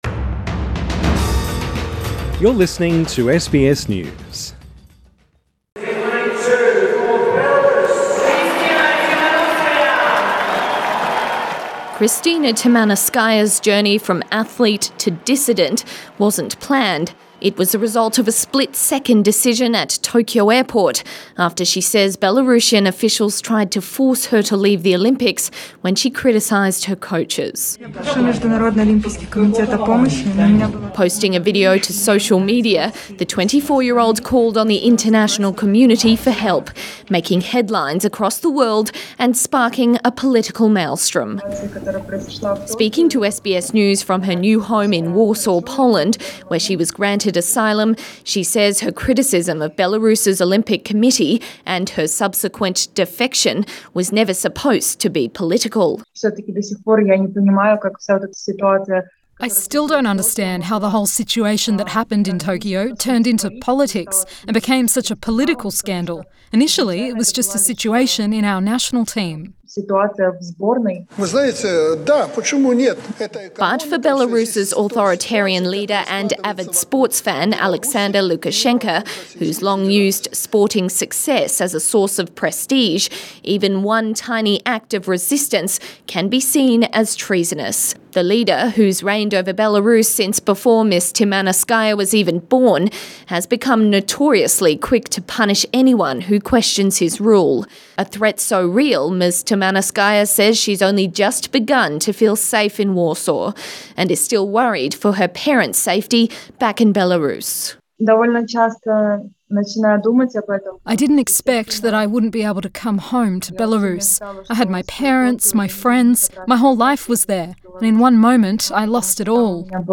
In her only Australian interview, Krystsina Tsimanouskaya told SBS News the country's authoritarian government has a history of intimidating and threatening athletes into silence.